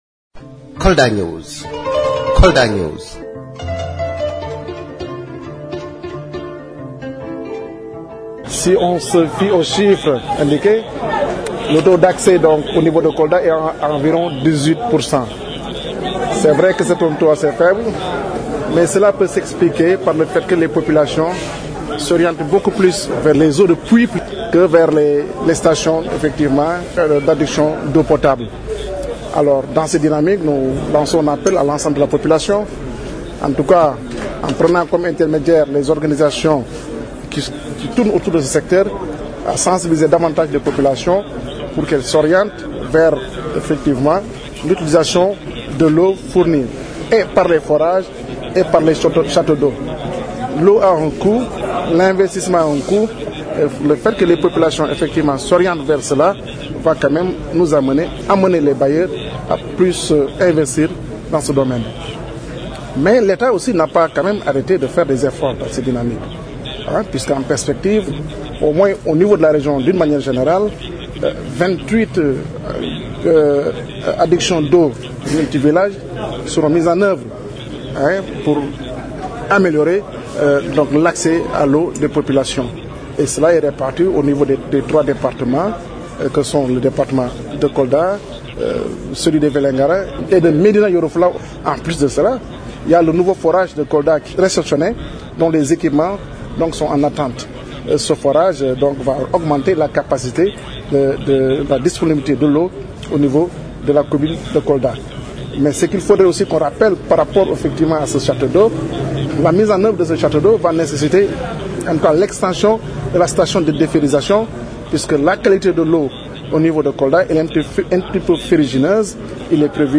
Ainsi, à l’occasion d’un CRD qu’il a présidé ce mardi dans la capitale du Fouladou, le Ministre de l’Hydraulique et de l’Assainissement invite les populations à changer de comportement pour consommer l’eau de robinet.